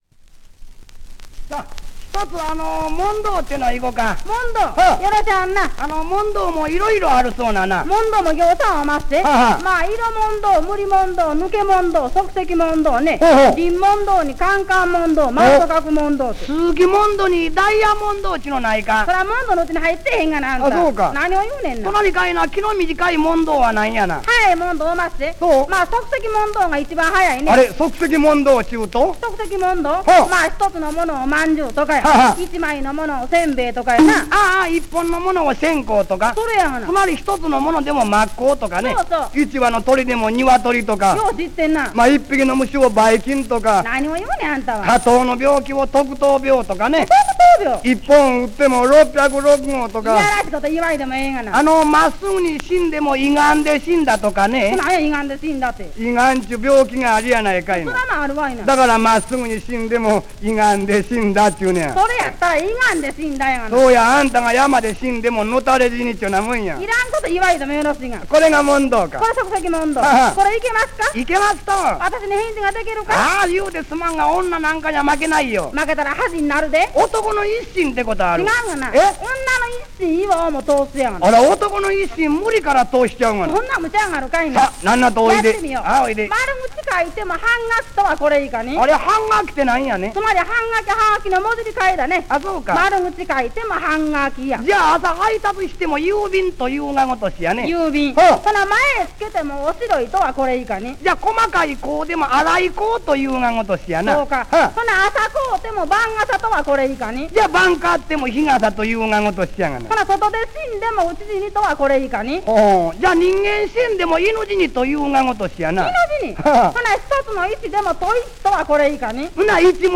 昭和期の生きた話し言葉（東京｜上方）のコーパス登場
SPレコード文句集　漫才編　データサンプル